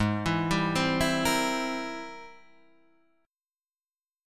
Ab+M9 chord